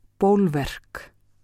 framburður
ból-verk